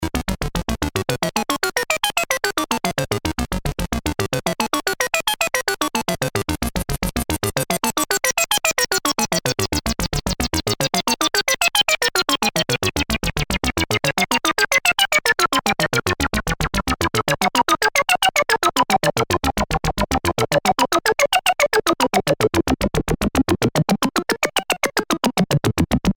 Sampled LFO through BandPass
Sample & Hold sampling triangle wave from LFO. VCO1 and VCO2 set to Saw, with VCO1 sync'd to VCO2. Run through BandPass filter with high resonance, sweeping the corner frequency manually.
lfo_sh_bp.mp3